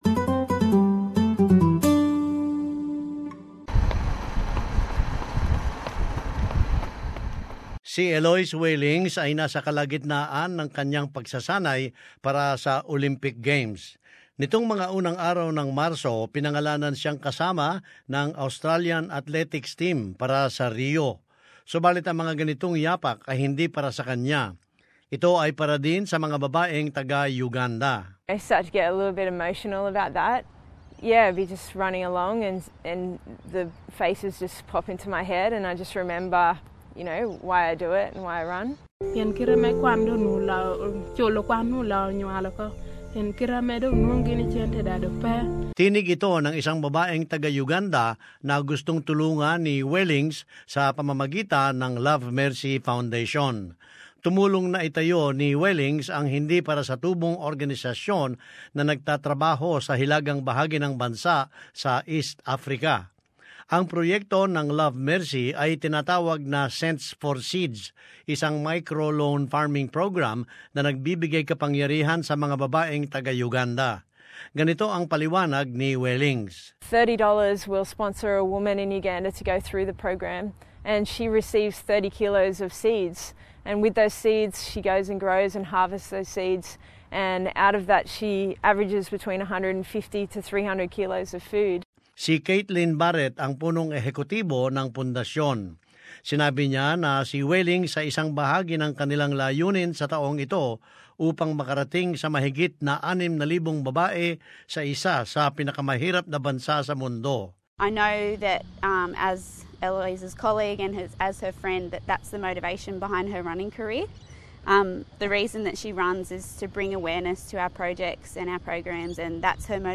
And this report shows, she is hoping her success on the track will mean a brighter future for Ugandan women off it.